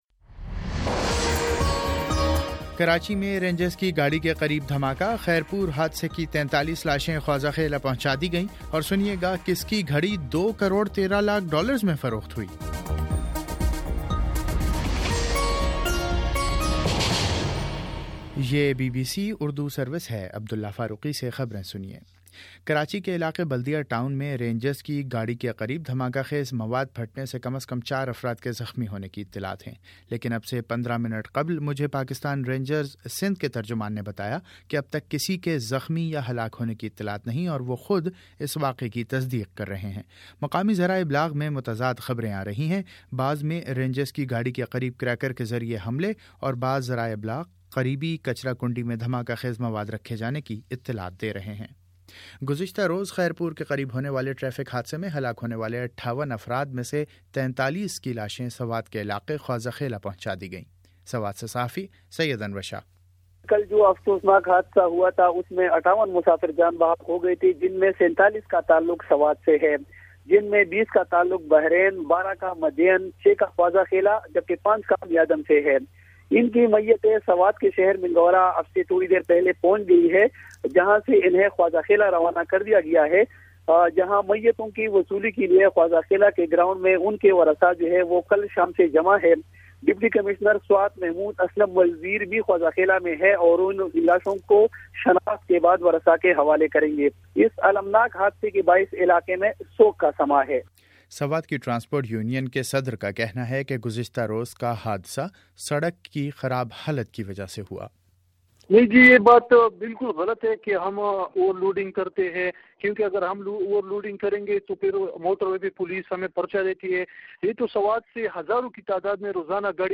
نومبر12 : صبح نو بجے کا نیوز بُلیٹن
دس منٹ کا نیوز بُلیٹن روزانہ پاکستانی وقت کے مطابق صبح 9 بجے، شام 6 بجے اور پھر 7 بجے۔